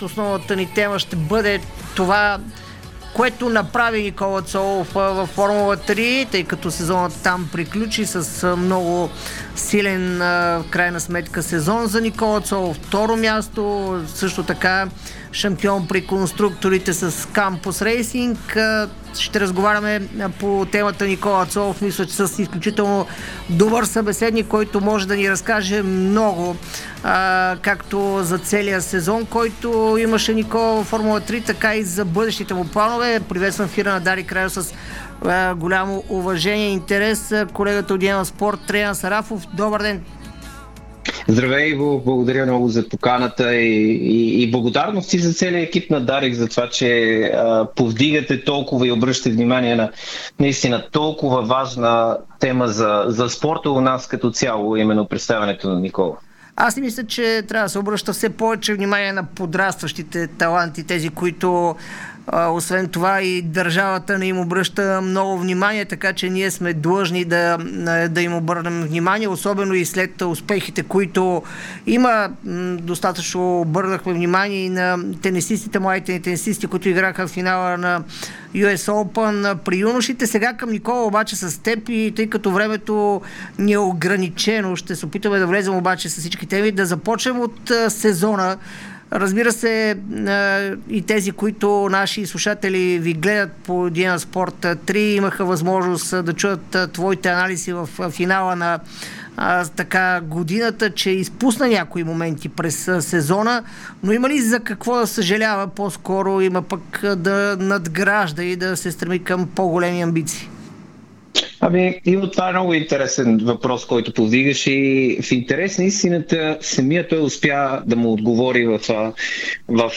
Чуйте целия разговор